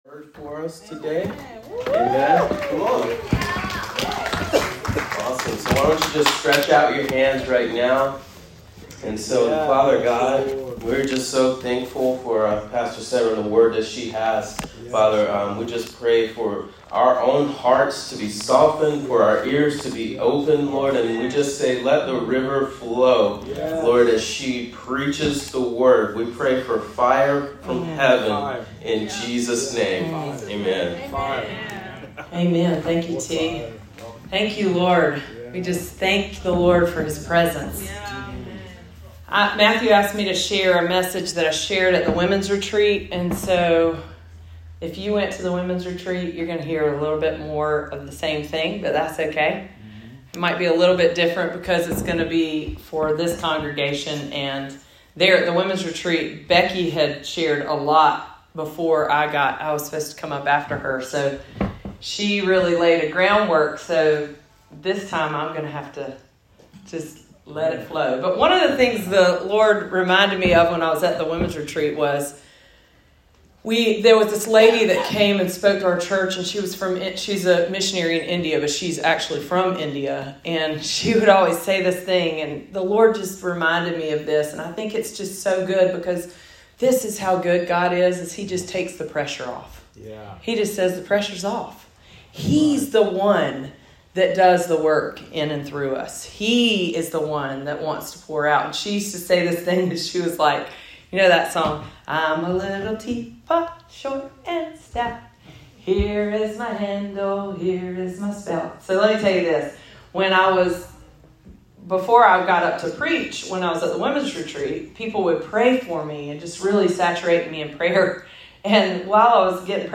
Sermon of the Week: 11/13/22 – RiverLife Fellowship Church